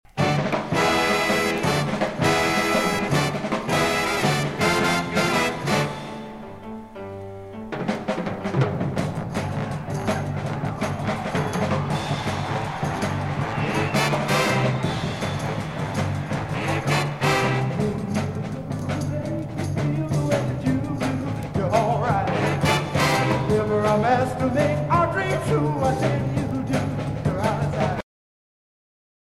vocals
trombone
alto saxophone
baritone saxophone
piano
percussion
Jazz vocals